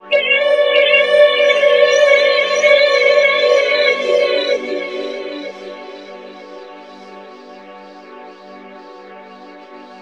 SCREAMVOC -L.wav